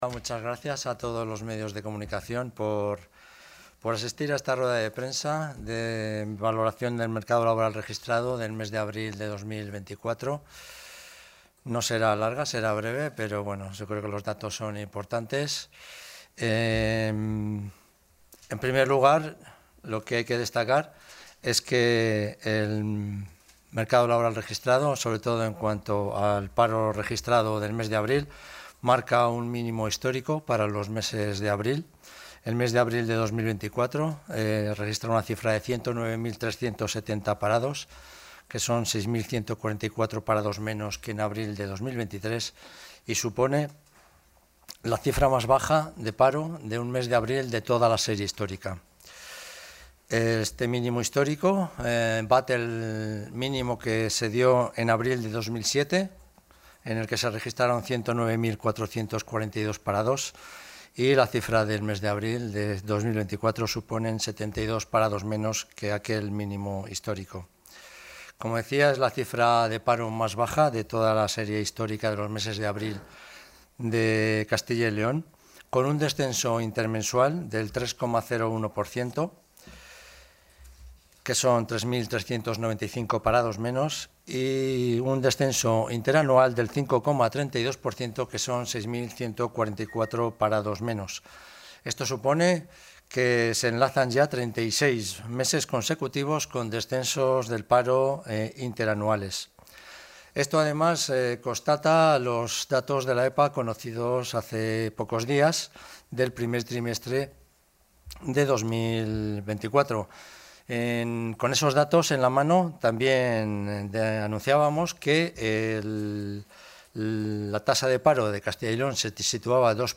El consejero de Industria, Comercio y Empleo, Mariano Veganzones, ha valorado hoy los datos de paro registrado correspondientes al mes de abril.